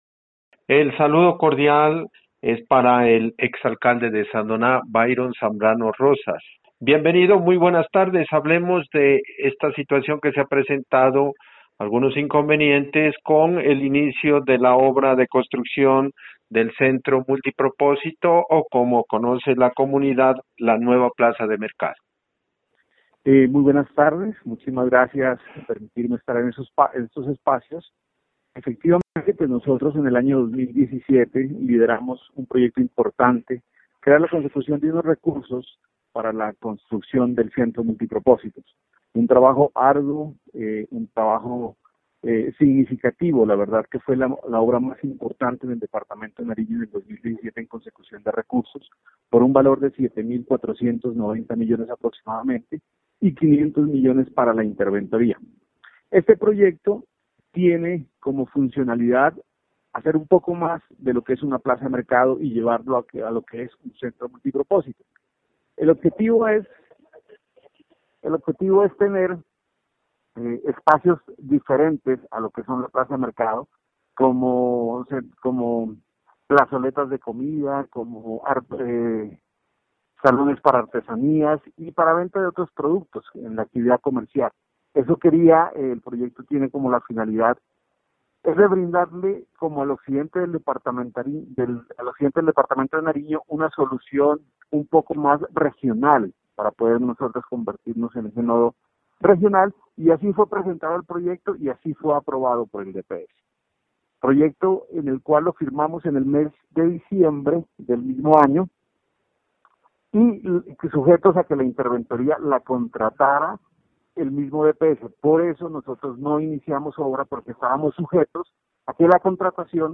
Entrevista con el ingeniero Byron Zambrano Rosas: